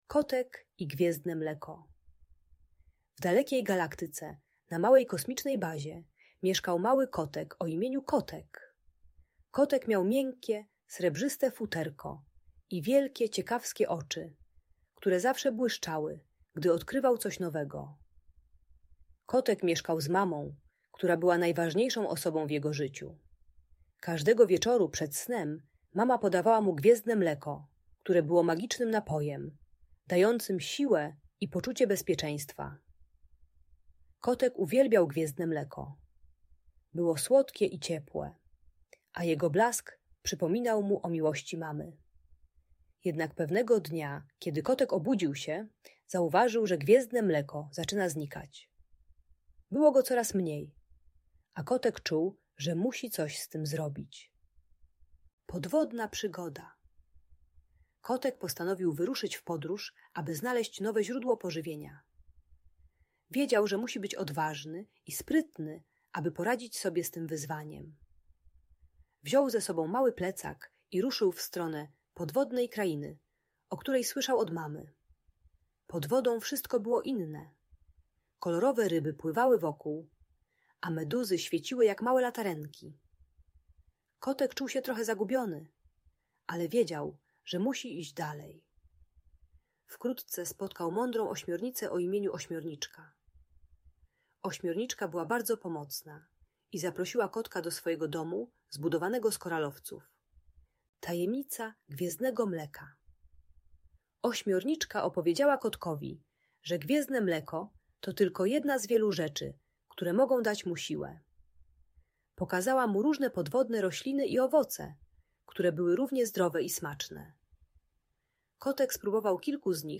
Kotek i Gwiezdne Mleko - Audiobajka dla dzieci